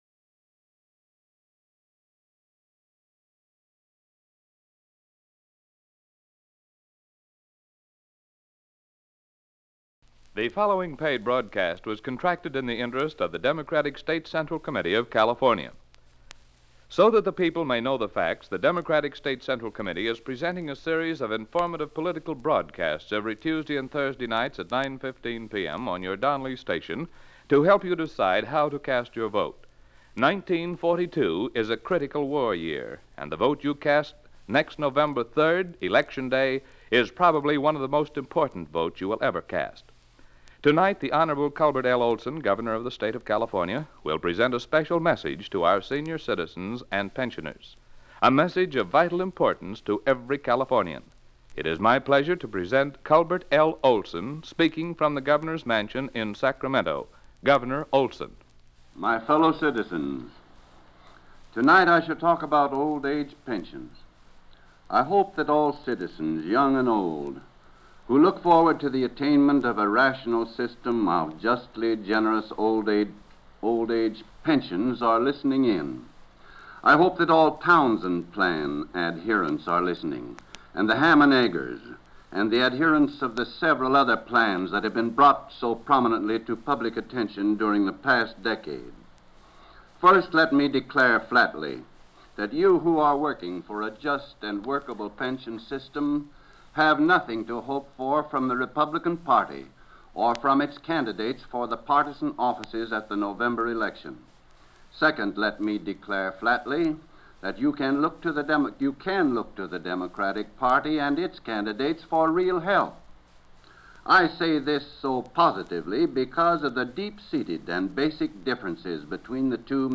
Captions English 1942 Culbert Olson Gubernatorial Campaign Speech American English Campaign speech on old age assistance and pension benefits.